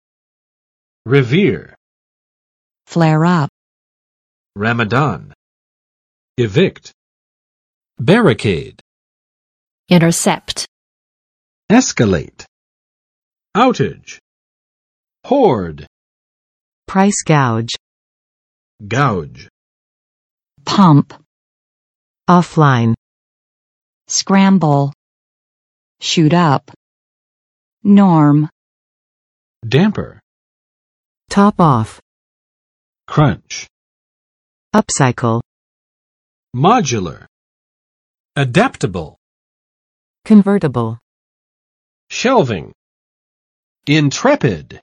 [rɪˋvɪr] v.【书】尊敬，崇敬；敬畏